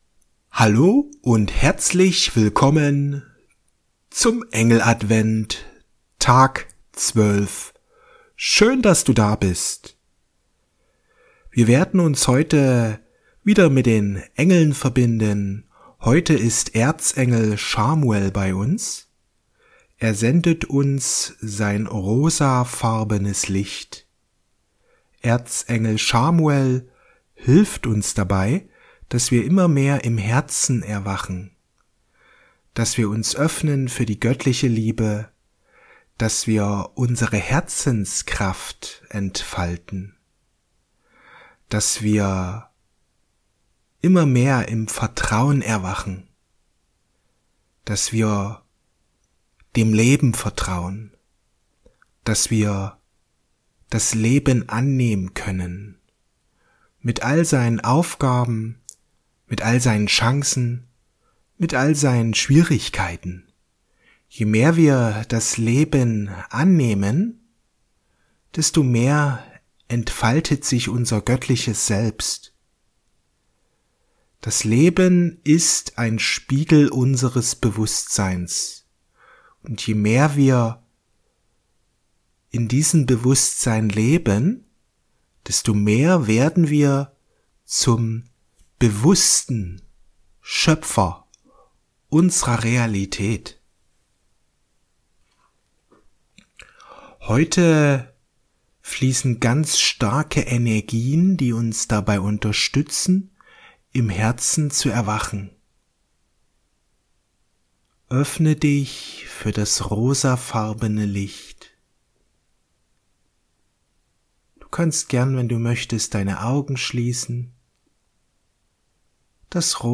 Vertrauen ins Leben Meditation mit Erzengel Chamuel